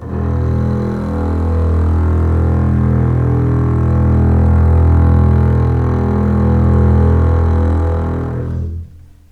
E1 LEG MF  R.wav